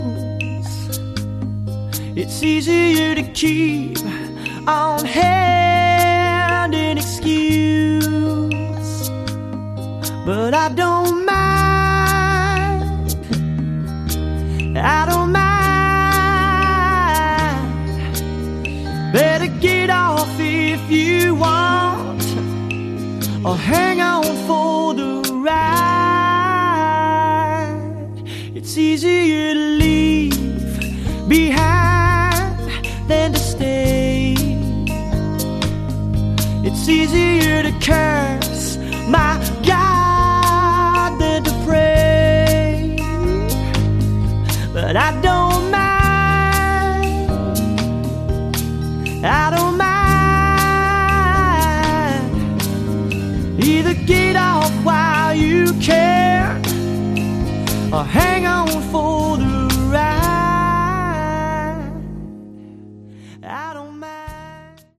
Category: Glammish Hard Rock
lead vocals
drums
bass, vocals
guitar, vocals